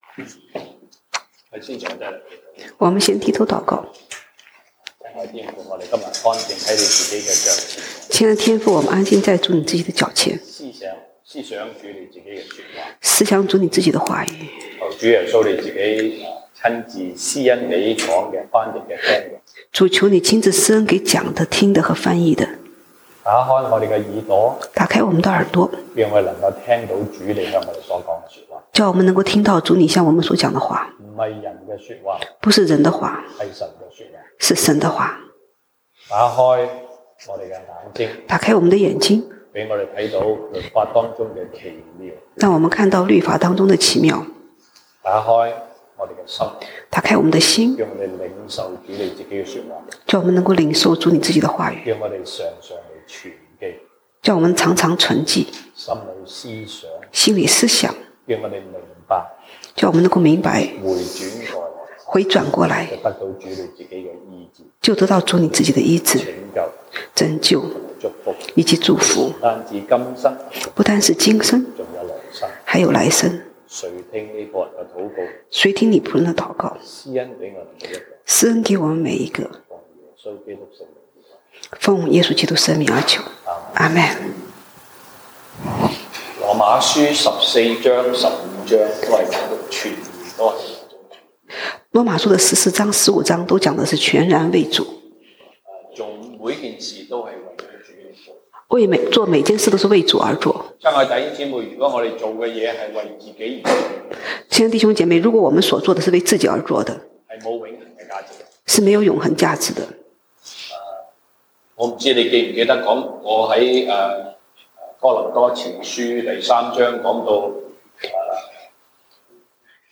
西堂證道(粵語/國語) Sunday Service Chinese: 凡事都是為主